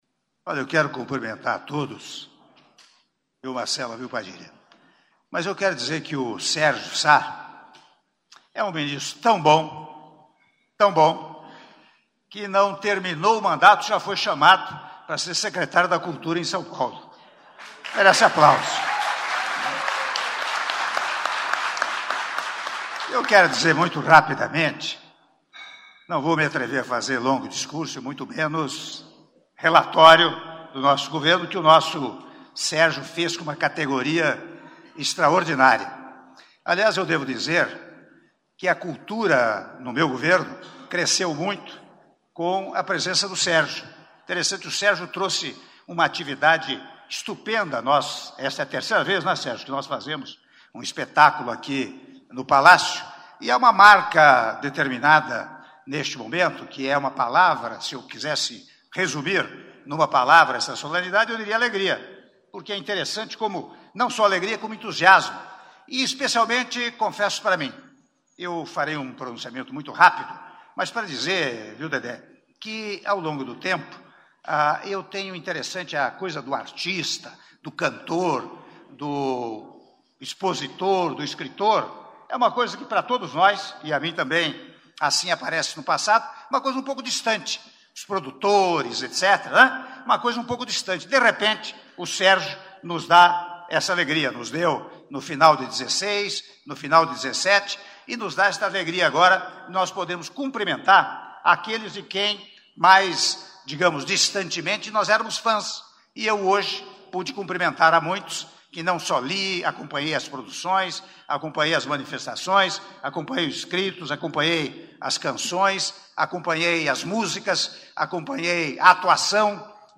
Áudio do discurso do Presidente da República, Michel Temer, durante Cerimônia de Entrega da Ordem do Mérito Cultural - OMC 2018 - Palácio do Planalto - (04min22s)